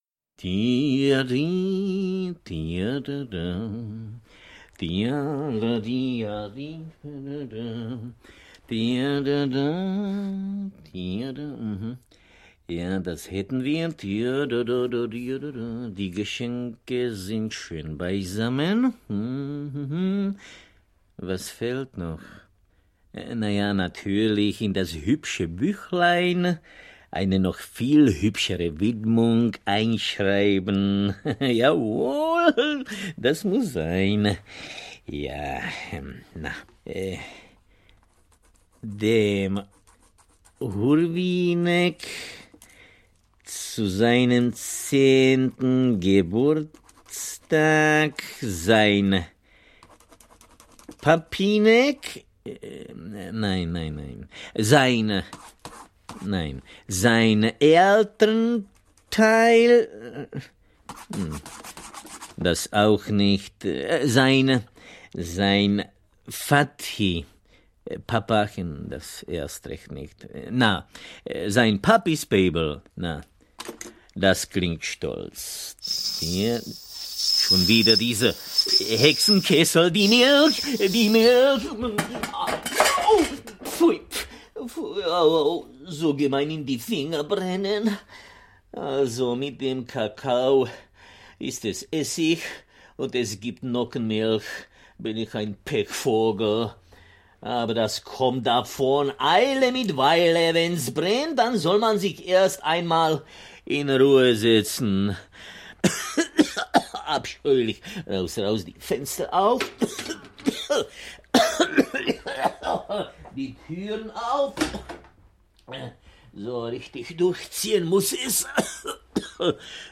Hurvínek, Mánička und Herr Spejbl zum Geburtstag audiokniha
Ukázka z knihy